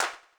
RX5 CLAP.wav